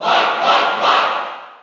File:Marth Cheer NTSC SSB4.ogg
Marth_Cheer_NTSC_SSB4.ogg.mp3